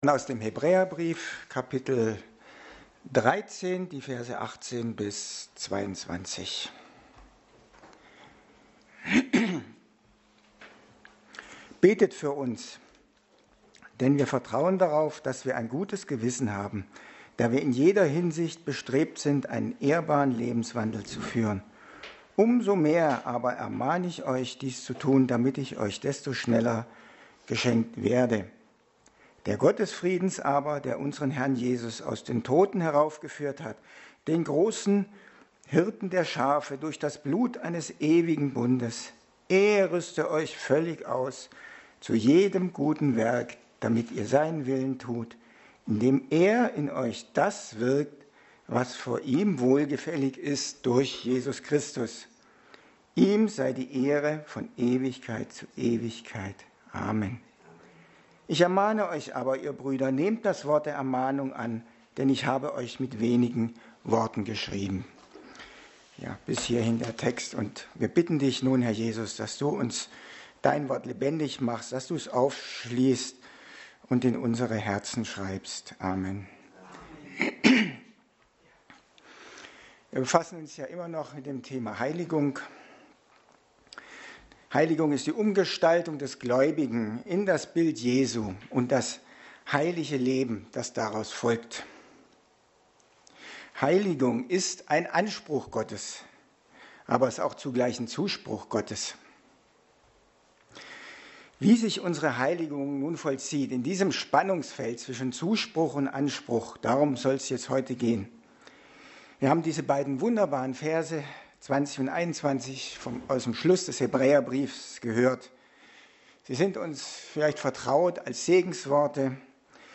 Passage: Hebräer 13,18-22; 12,1-15 Dienstart: Predigt